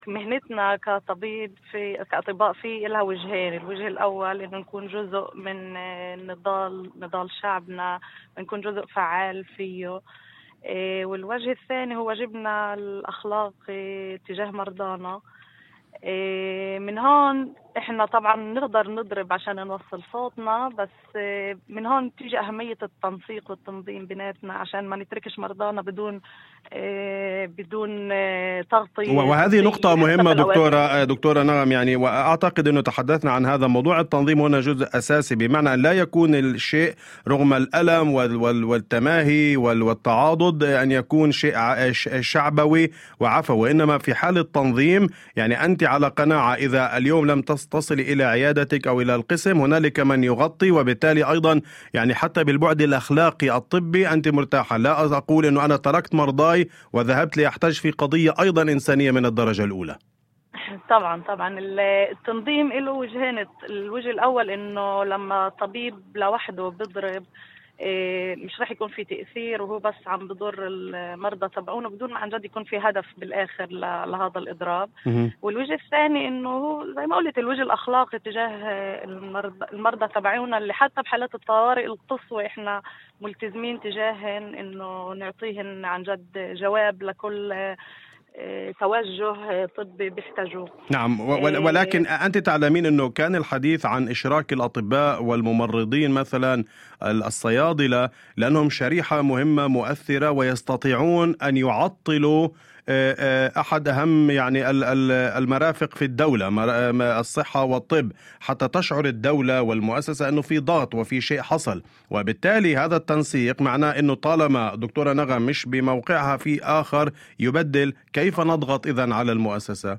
وأضافت في مداخلة هاتفية ضمن برنامج "أول خبر"، على إذاعة الشمس: